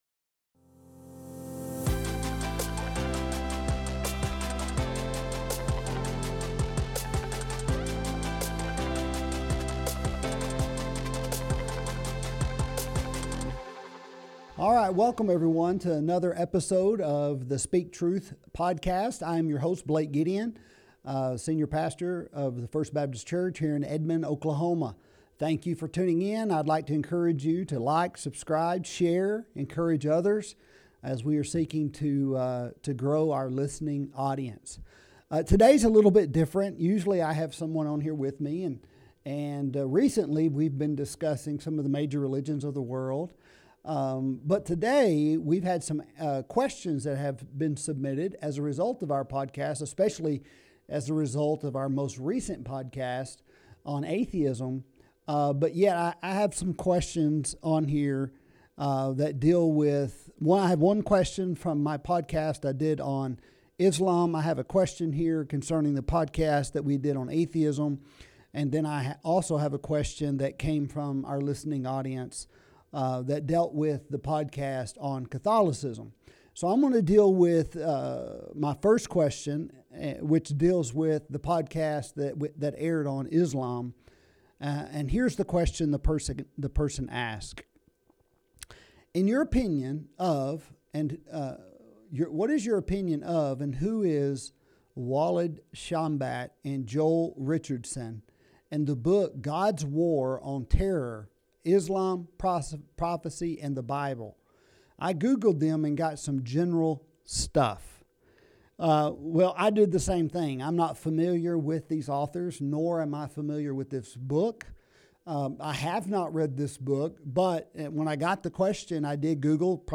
Religions Q&A